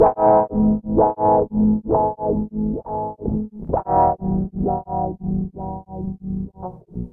65WHA -GTR-L.wav